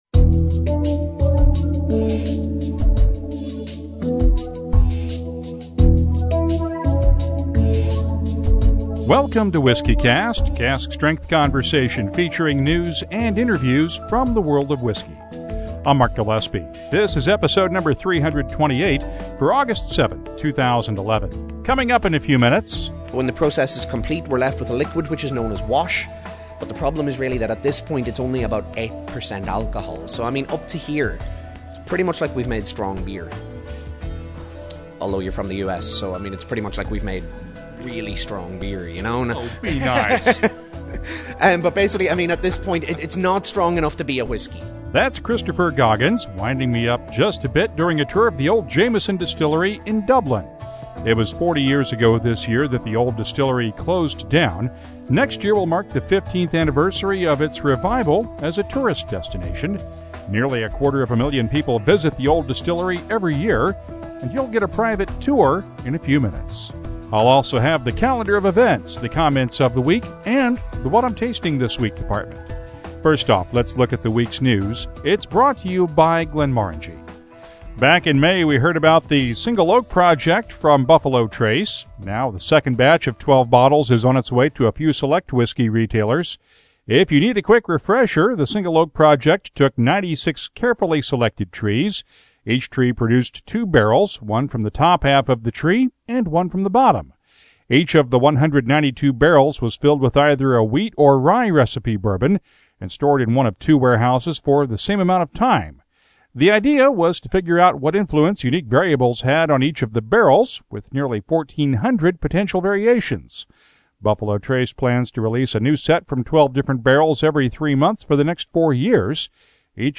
interview
on location in Dublin